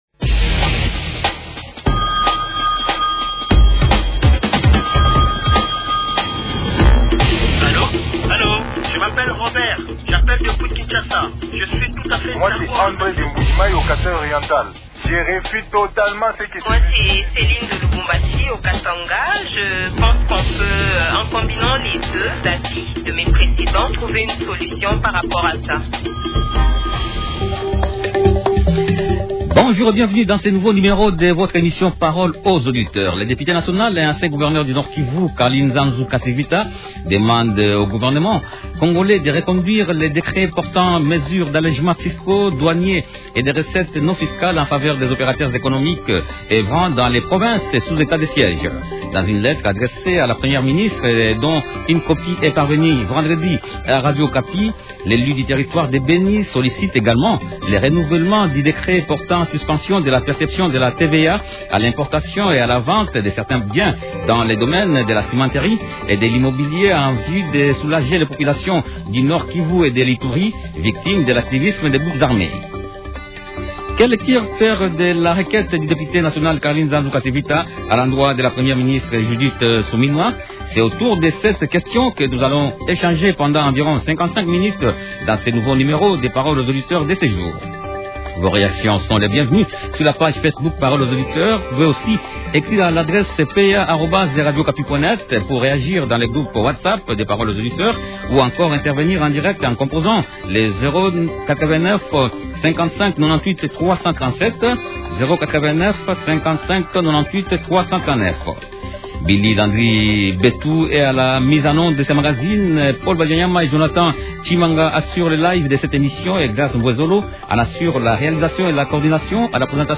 Invité: Carly Nzanzu Kasivita, député national élu de Beni territoire.